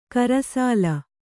♪ karasāla